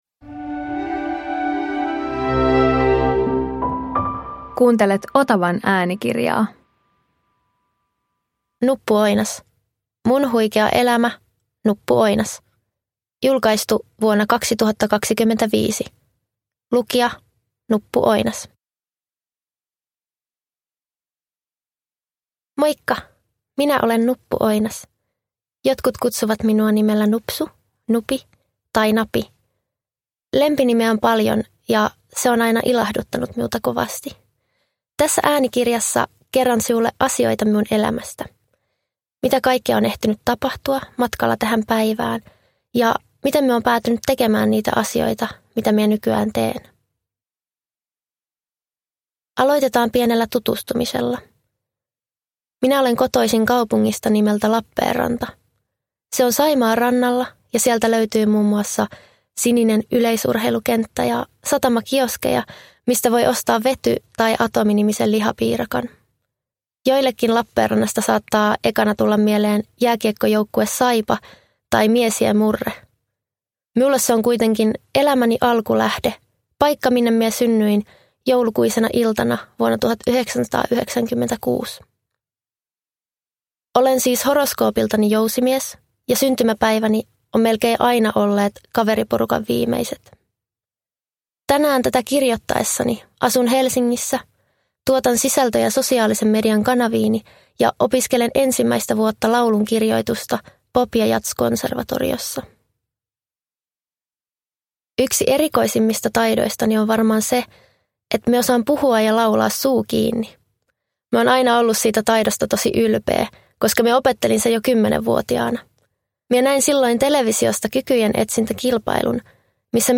Mun huikea elämä - Nuppu Oinas – Ljudbok
Uppläsare: Nuppu Oinas